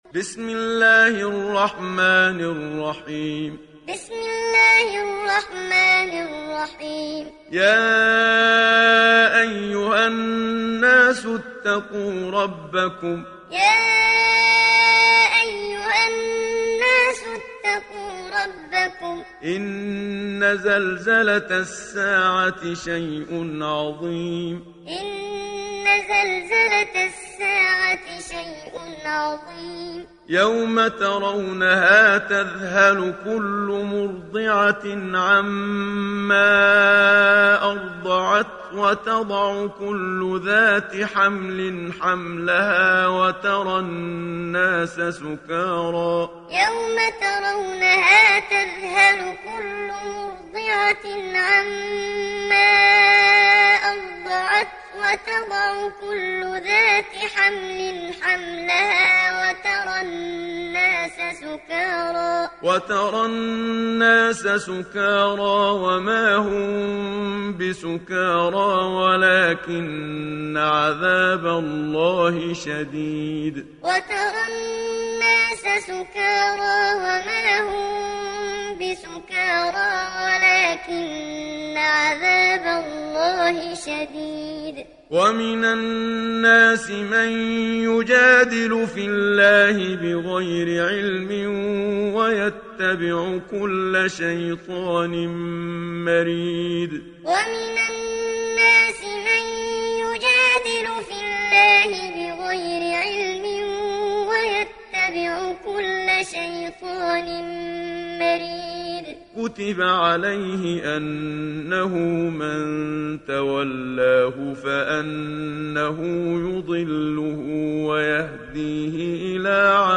تحميل سورة الحج mp3 بصوت محمد صديق المنشاوي معلم برواية حفص عن عاصم, تحميل استماع القرآن الكريم على الجوال mp3 كاملا بروابط مباشرة وسريعة
تحميل سورة الحج محمد صديق المنشاوي معلم